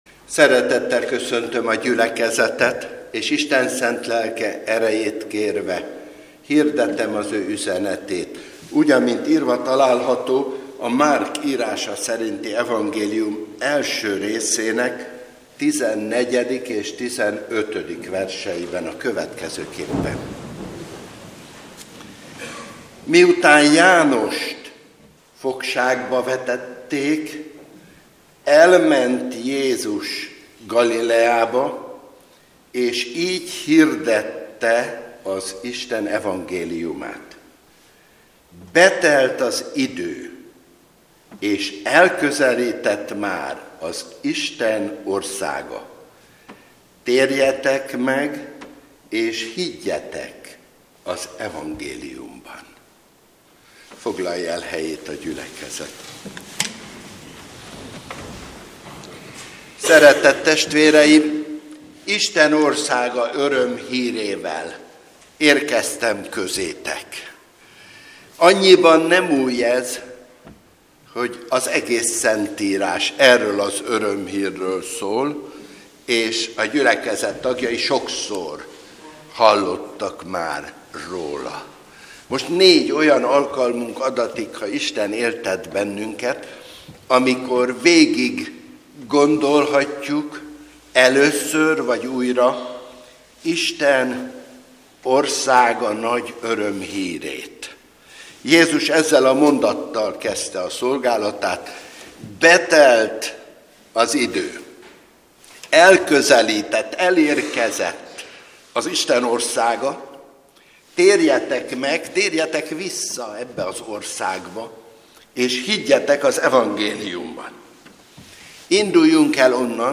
2016. február 21. EVANGÉLIZÁCIÓ I.